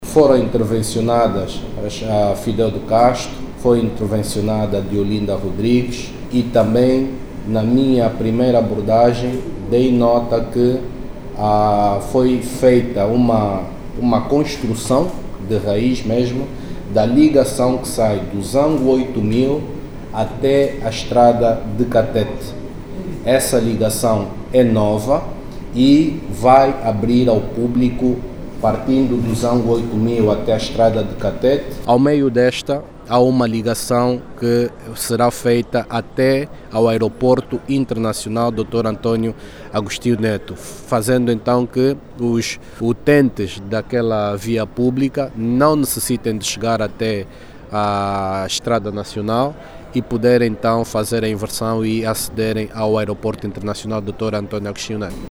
O anúncio foi deito pelo presidente do Conselho de administração da Agência Nacional dos Transportes Terrestres, Énio Magalhães, que já fala  na melhoria da mobilidade naquela zona.